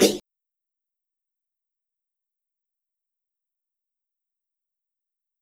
Snare